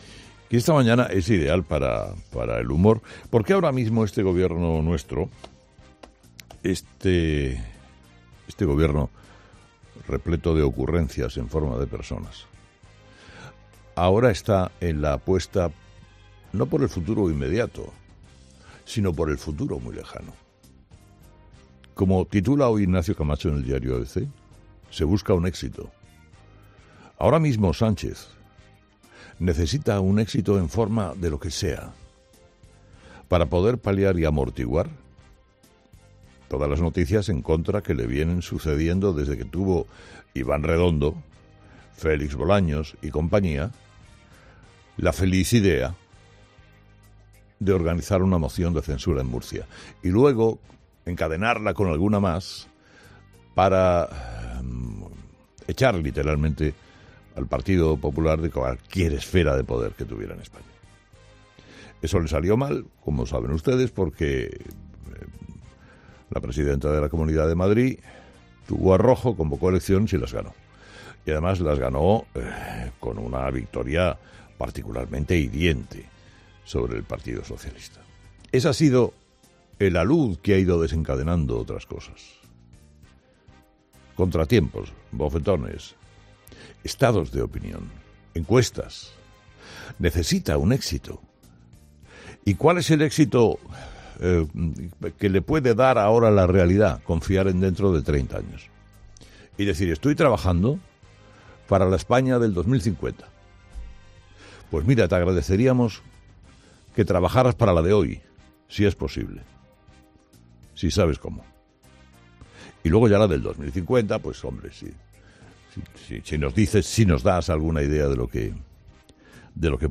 Carlos Herrera, director y presentador de 'Herrera en COPE' ha comenzado el programa de este lunes analizando las principales claves de la jornada, que viene marcada por los efectos del fin de semana sin estado de alarma y la resaca futbolística de una jornada de Liga de infarto.